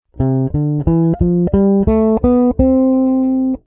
Ahora dale al play y escucha cómo suena la escala mayor con este audio:
Arriba ya has escuchado cómo suena la escala mayor tocada con el bajo.
como-suena-la-escala-mayor-en-bajo.mp3